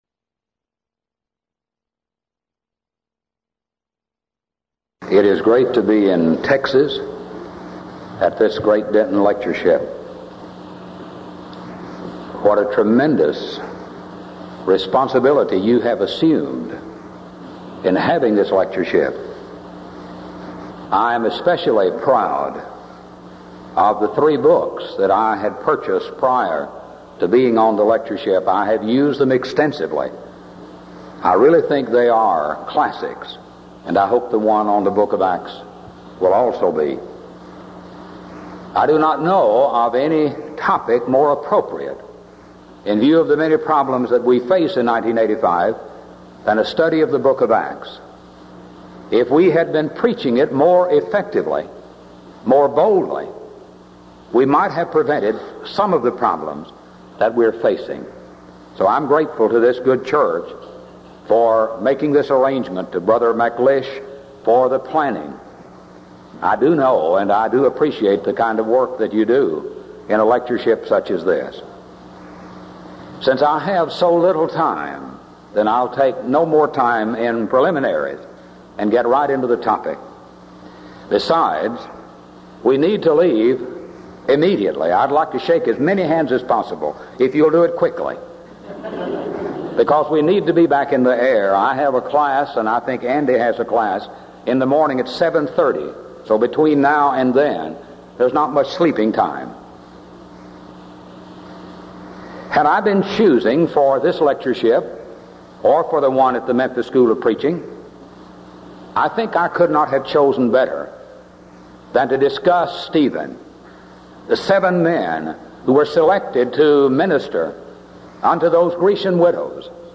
Event: 1985 Denton Lectures Theme/Title: Studies in Acts